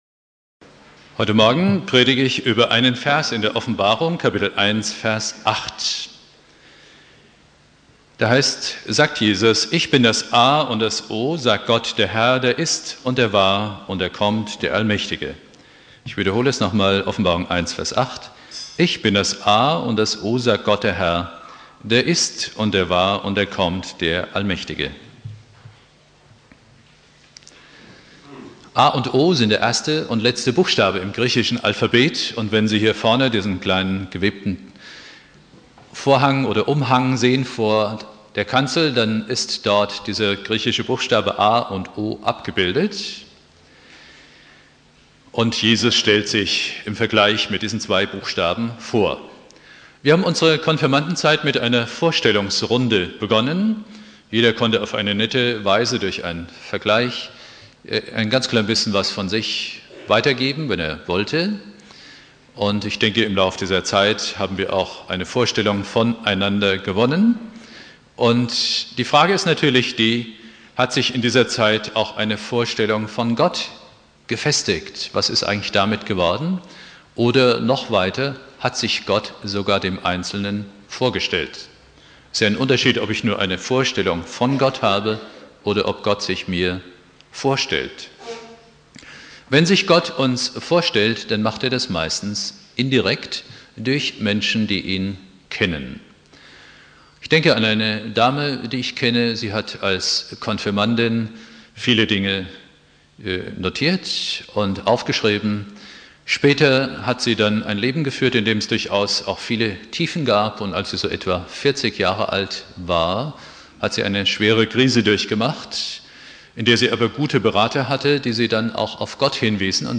Predigt
Thema: Ich bin das A und 0 (Konfirmation Obertshausen) Bibeltext: Offenbarung 1,8 Dauer